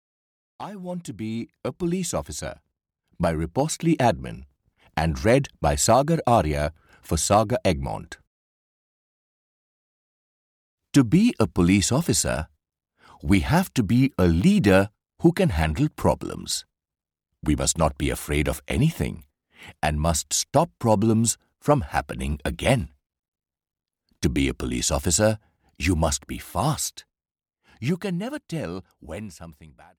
Audio knihaI Want to be a Police Officer (EN)
Ukázka z knihy